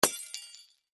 Звук разбившейся елочной игрушки